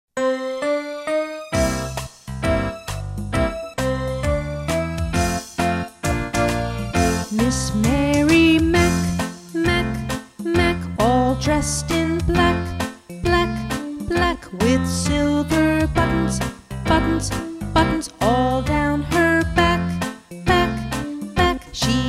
Listen to a sample of this slow version..